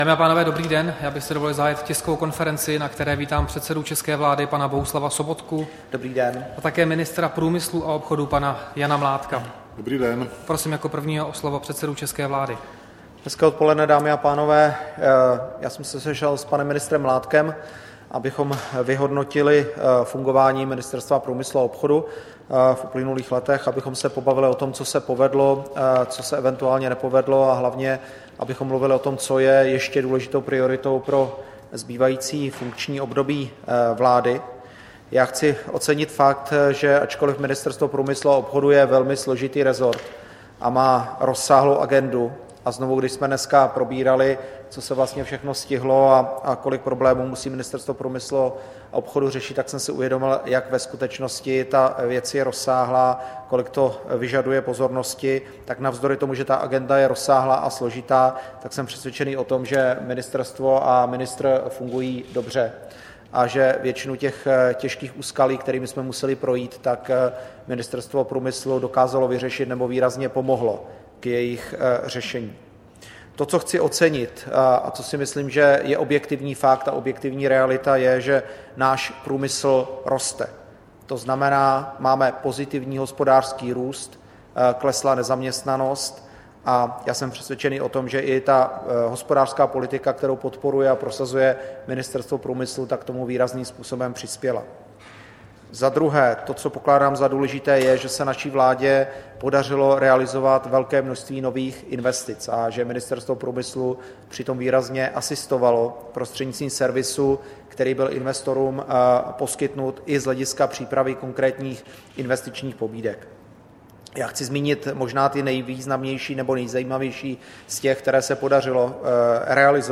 Tisková konference po jednání předsedy vlády Sobotky s ministrem průmyslu a obchodu Mládkem, 15. listopadu 2016